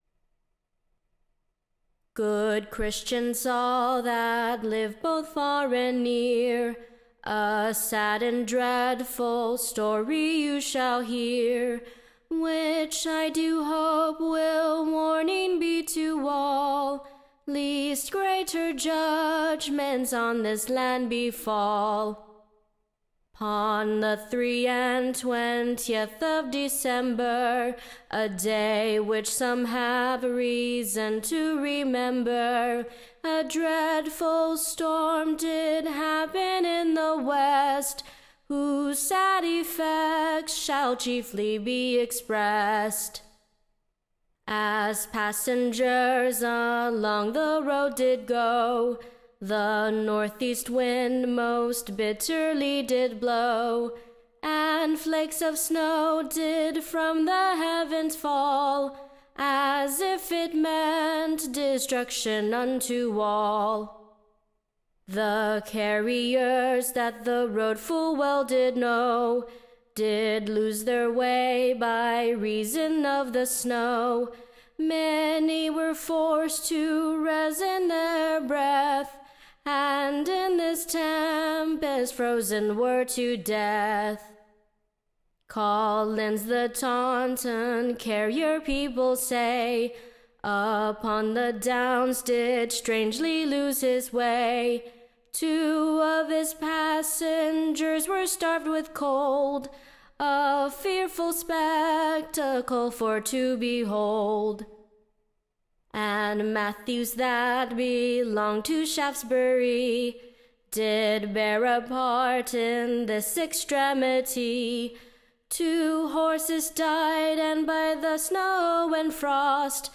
Recording Information Ballad Title Sad News from Salisbury, / And other Parts of the West of ENGLAND.